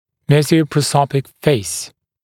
[ˌmesəuprə’səupik feɪs] [-‘sɔp-][ˌмэзоупрэ’соупик фэйс] [-‘соп-]мезопрозопное лицо, короткое лицо